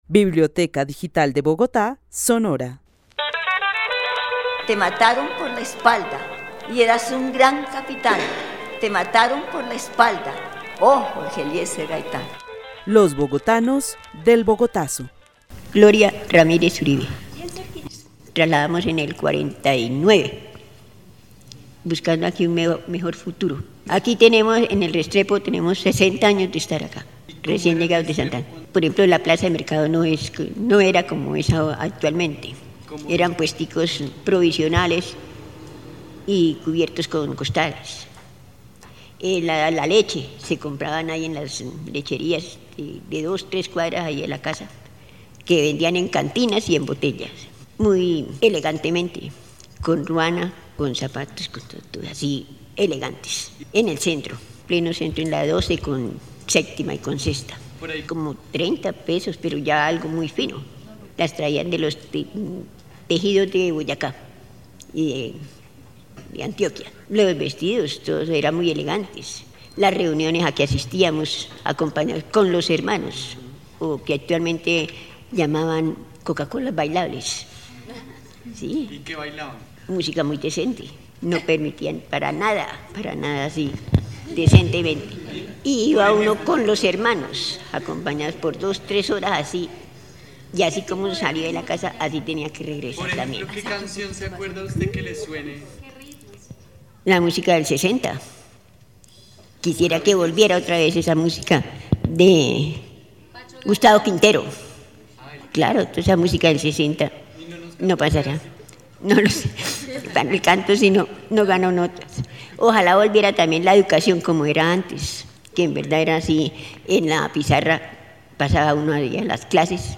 Narración oral sobre la vida en el barrio Restrepo en los años 60. La narradora hace una descripción del territorio de Bogotá y sus costumbres (vestimenta, comercio, educación, celebraciones, hábitos, vida familiar, etc.) en esa época. El testimonio fue grabado en el marco de la actividad "Los bogotanos del Bogotazo" con el club de adultos mayores de la Biblioteca Carlos E. Restrepo.